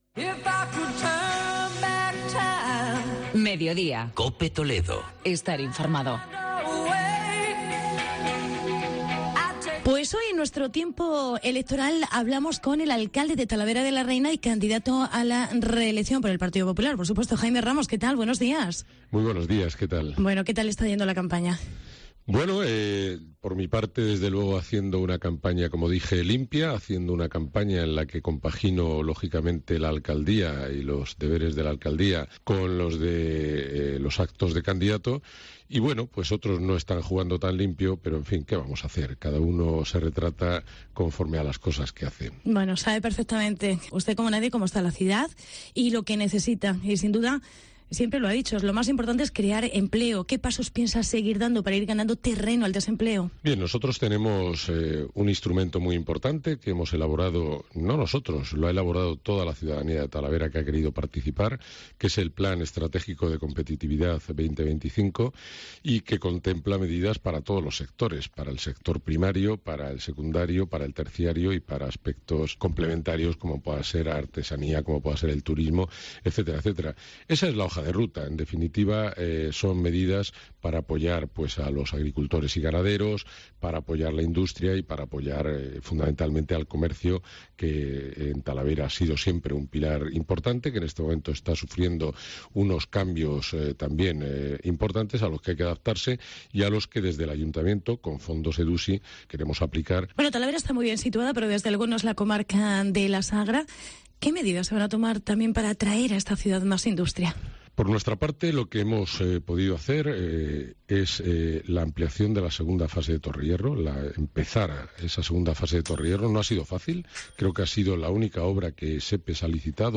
Entrevista con Jaime Ramos. Alcalde de Talavera y candidato a la reelección